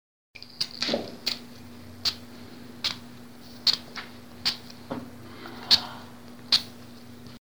Lanzando y recogiendo una pelota
Me gusta Descripción Grabación sonora que capta el sonido de alguien que lanza al aire y recoge una pelota entre sus manos. Sonidos cotidianos